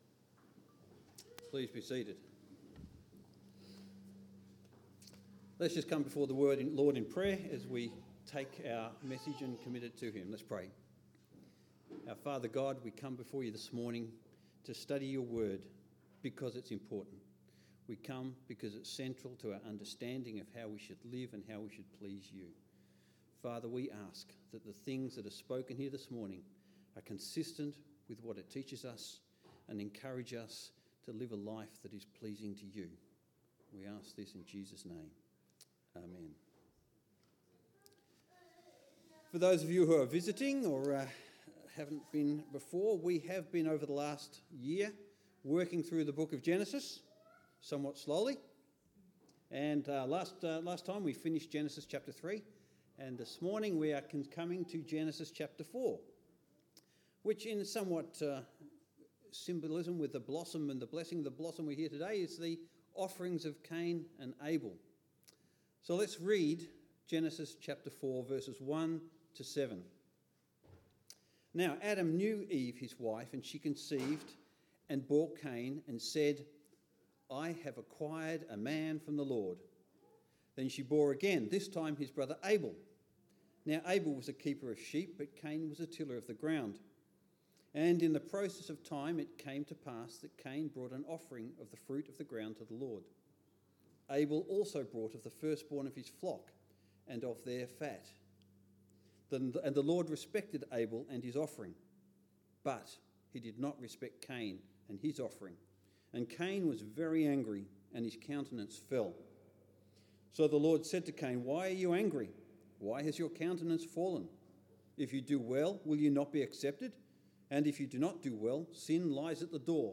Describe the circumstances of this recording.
Blessing of the Blossoms service at St. John’s Church in Franklin, Tasmania.